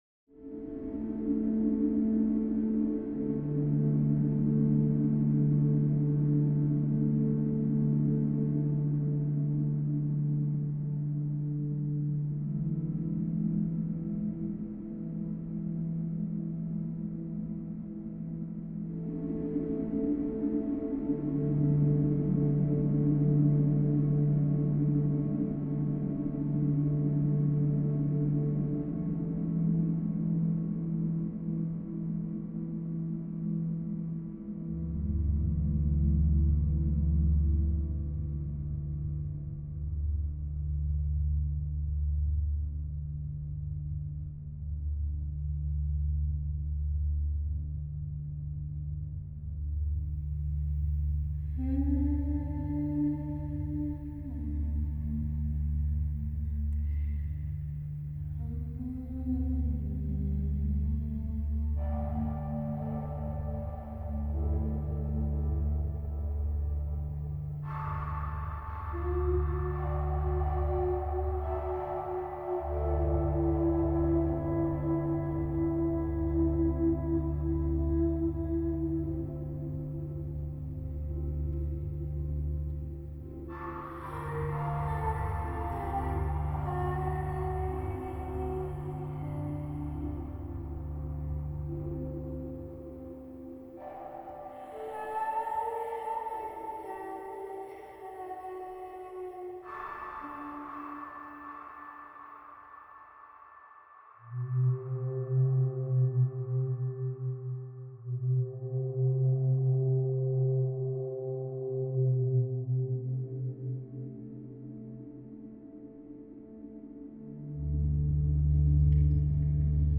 Sound composition